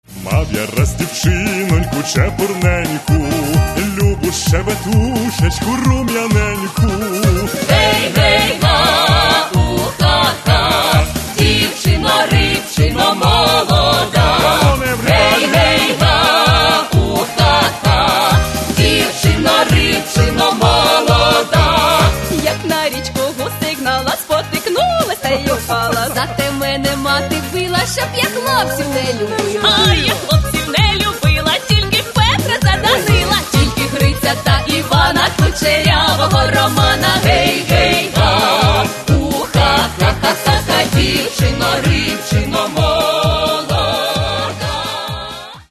Каталог -> Народна -> Ансамблі народної музики